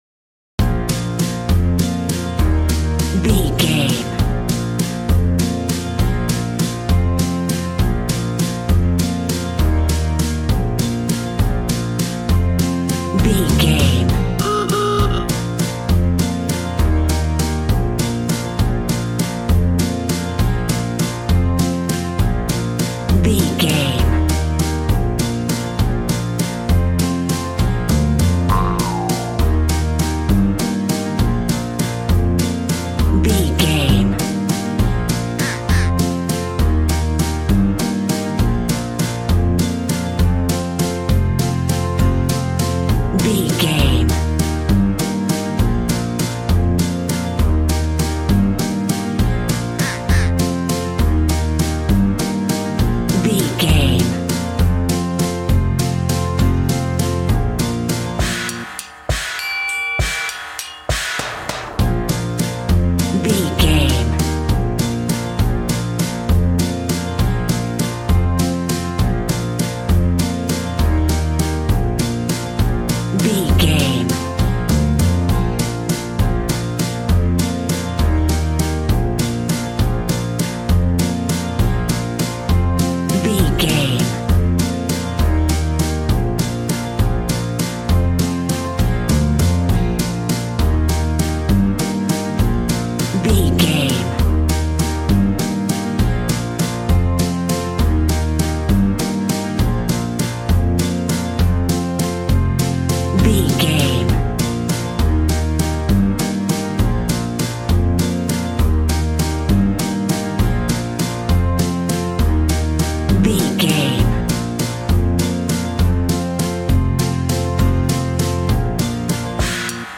Ionian/Major
B♭
cheerful/happy
bouncy
electric piano
electric guitar
drum machine